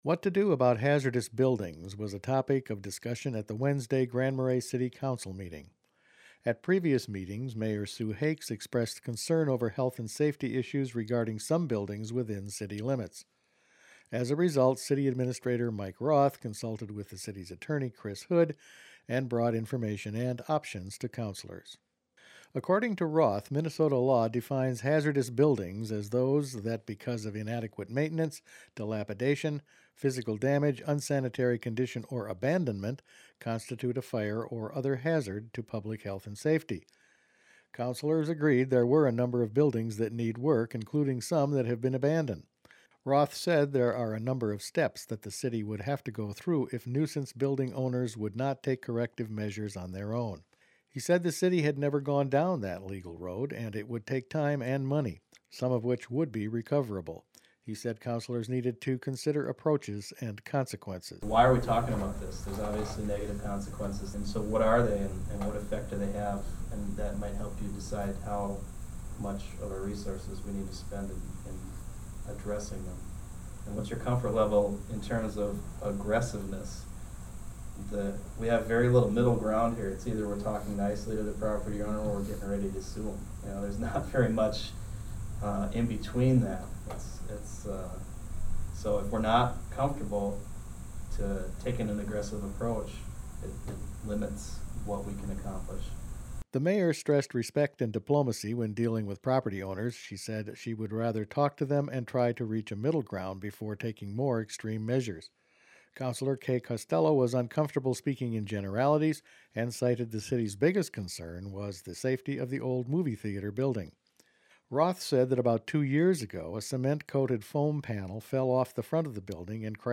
What to do about hazardous buildings was a topic of discussion at the Wednesday Grand Marais City Council meeting.